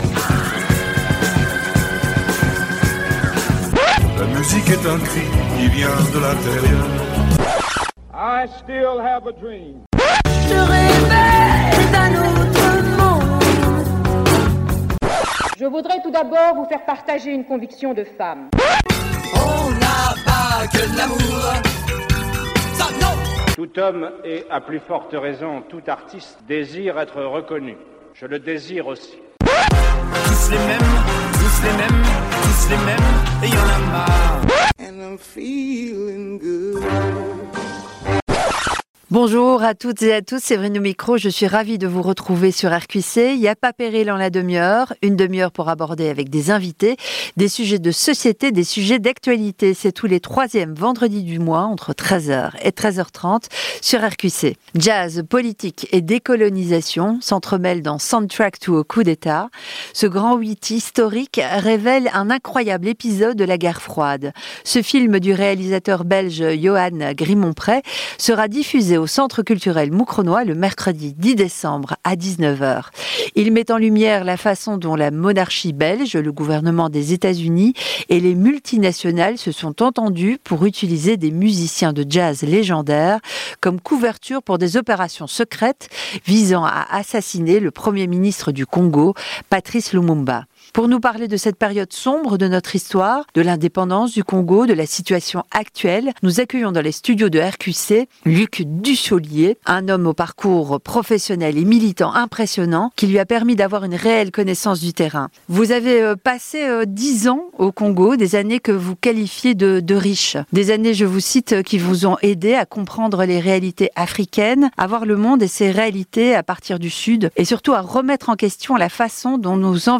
Pour nous parler de cette période sombre de notre histoire, de l’indépendance du Congo, de la situation actuelle, nous accueillons dans les studios de RQC